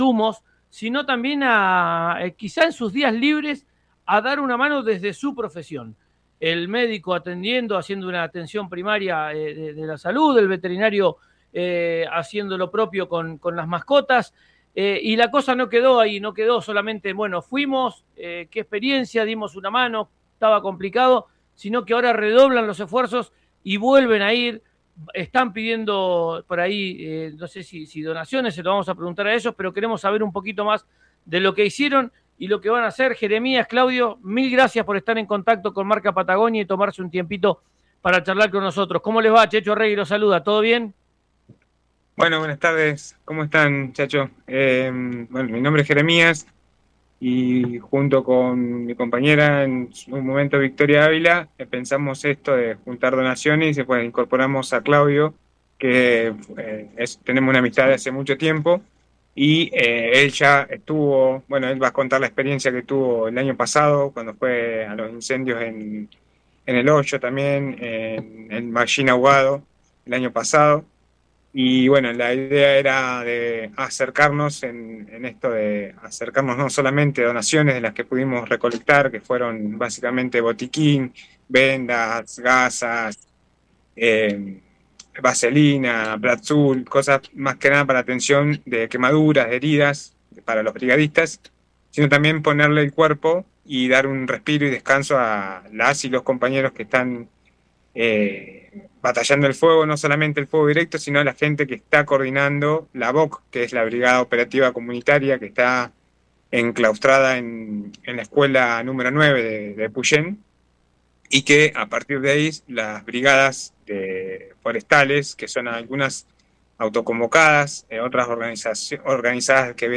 médico